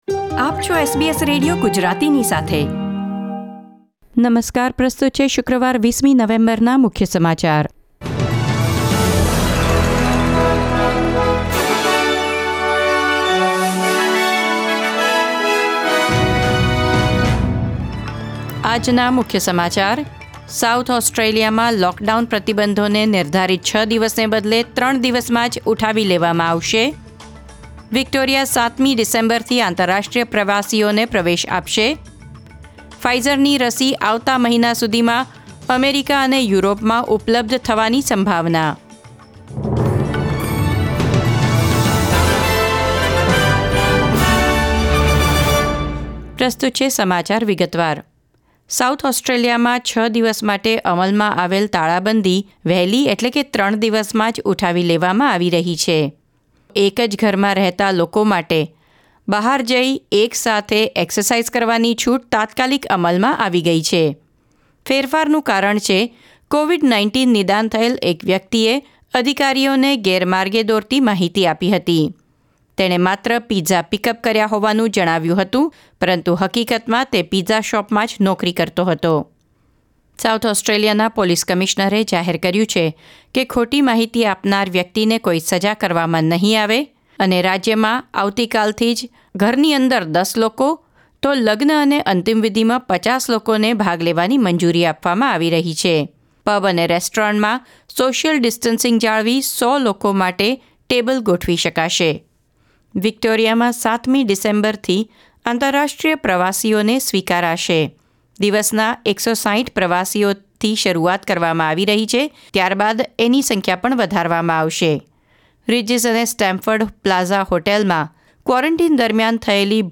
SBS Gujarati News Bulletin 20 November 2020